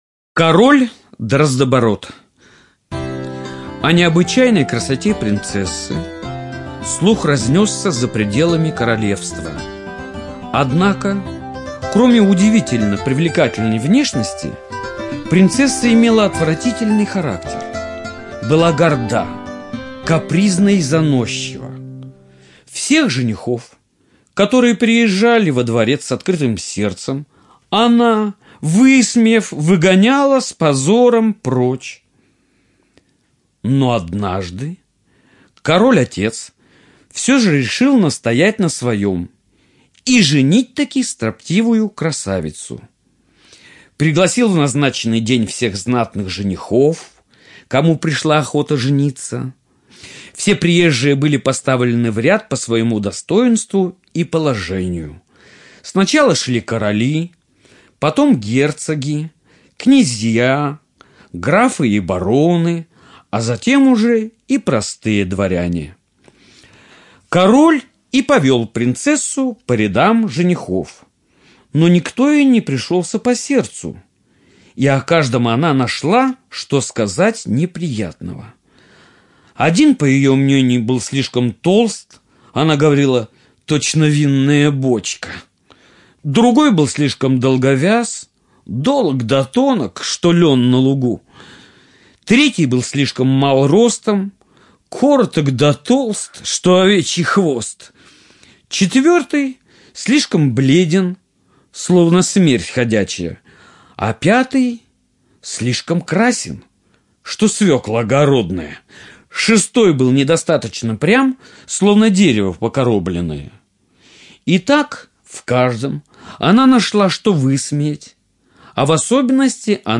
Король Дроздобород - аудиосказка братьев Гримм. Сказка о надменной принцессе, которая насмехалась над всеми претендентами на ее руку.